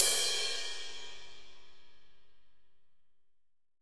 Index of /90_sSampleCDs/AKAI S6000 CD-ROM - Volume 3/Crash_Cymbal1/15-18_INCH_AMB_CRASH
16AMB CRS2-S.WAV